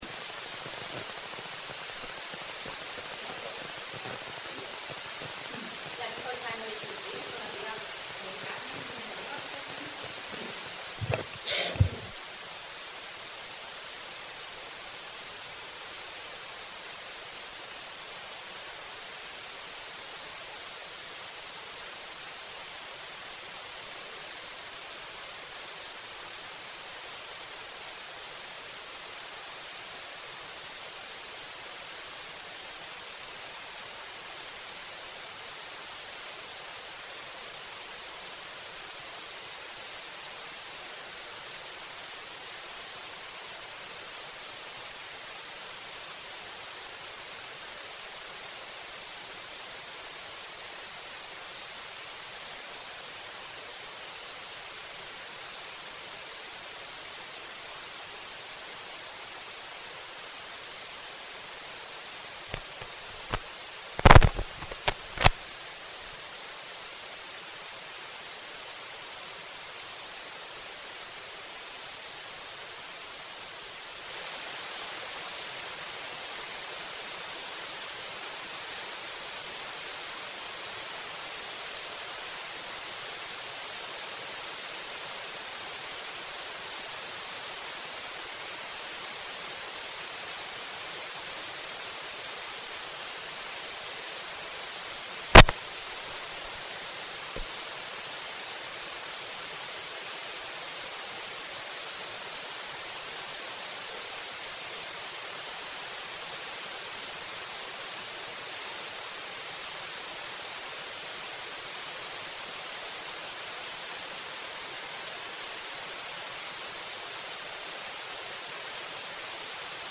Dienstart: Externe Prediger